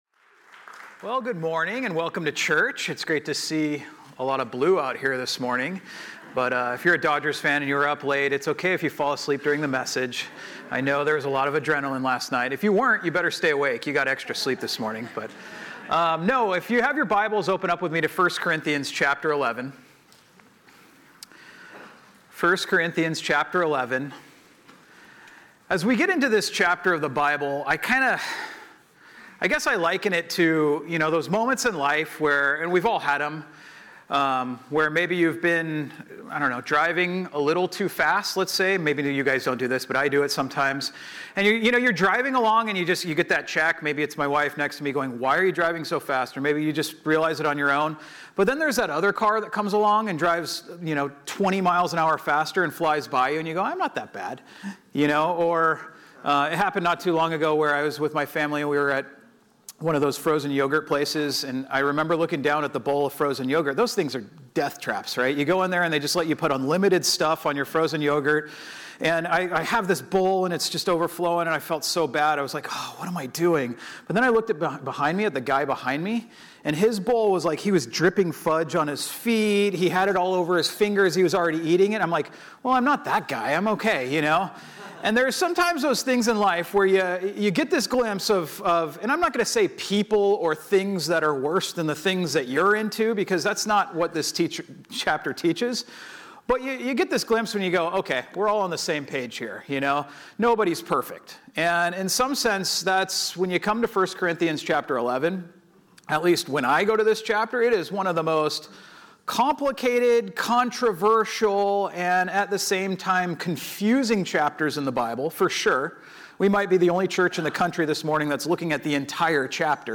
Messages | Pacific Hills Calvary Chapel | Orange County | Local Church